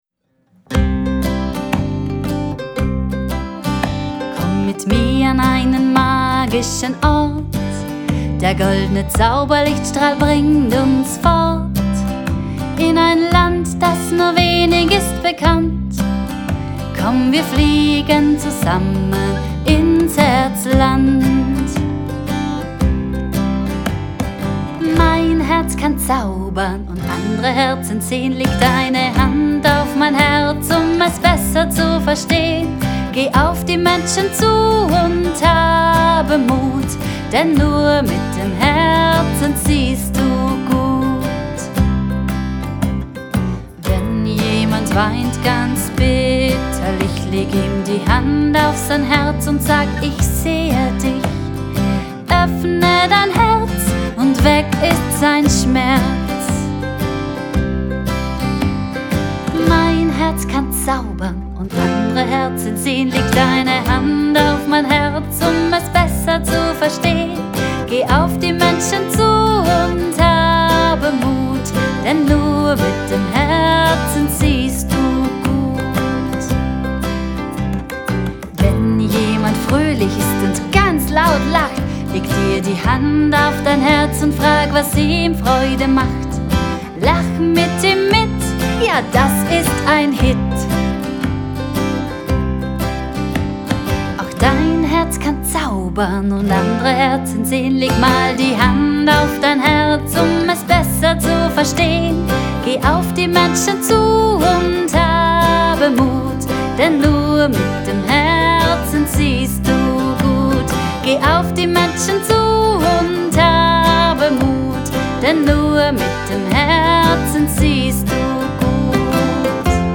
“HERZLAND”. Genre: Blues.